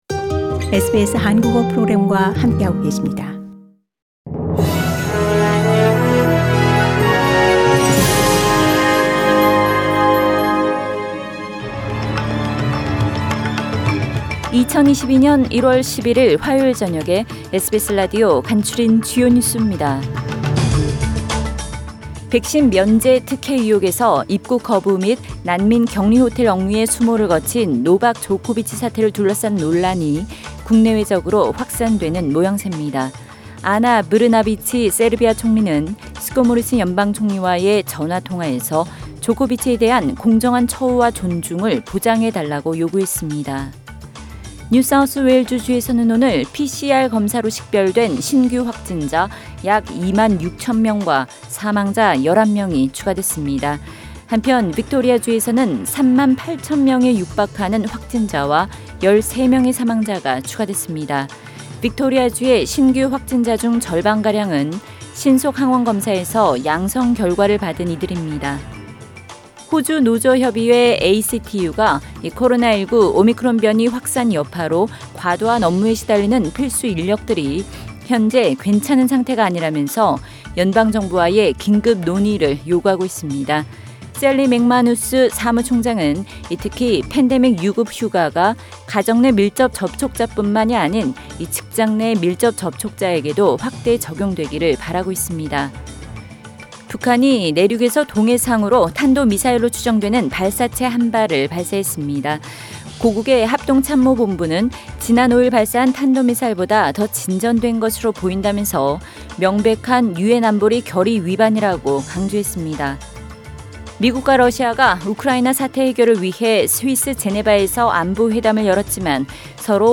SBS News Outlines…2022년 1월 11일 저녁 주요 뉴스